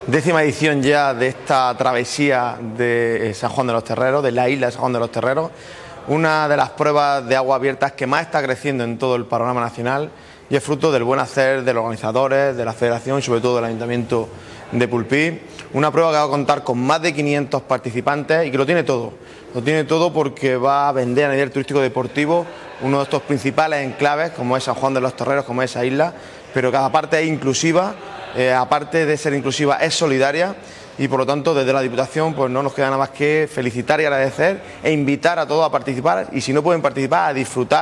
El acto de presentación en el Pabellón Moisés Ruiz ha contado con la participación del vicepresidente y diputado de Deportes